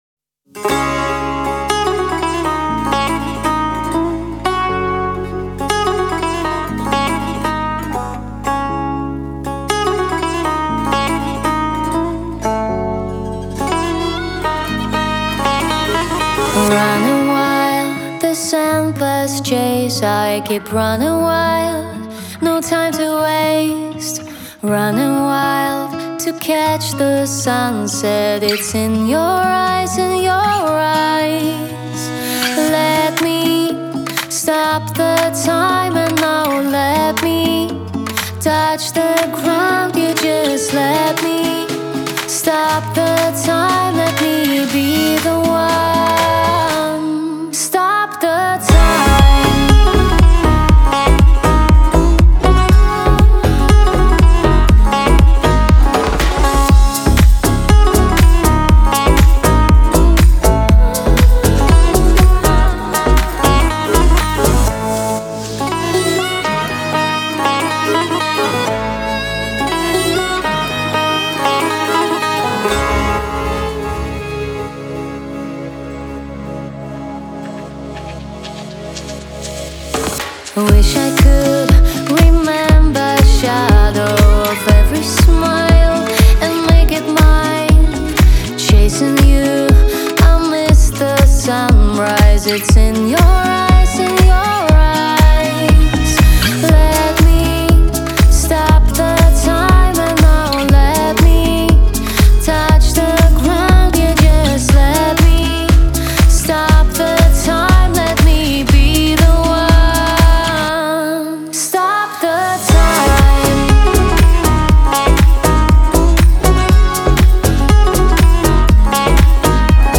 это завораживающая трек в жанре электронной музыки